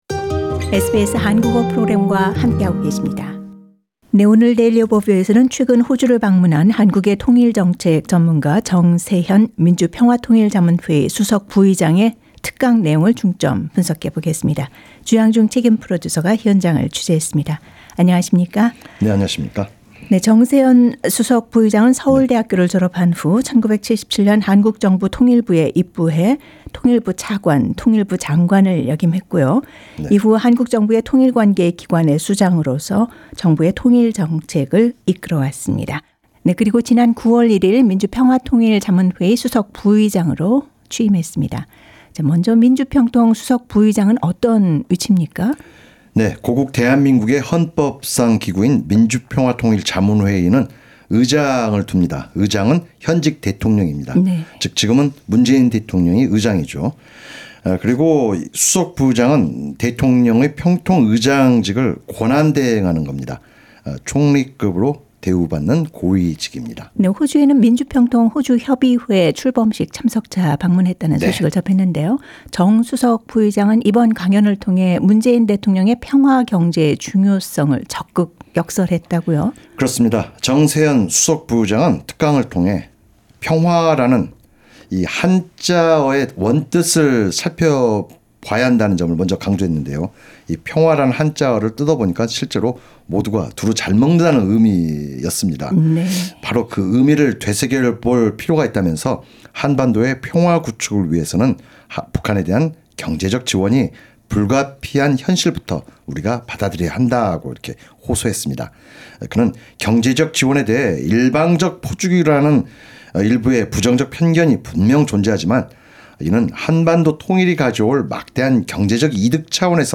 Mr Jeong Se-hyun delivers a special lecture on peace economy and unification in Sydney.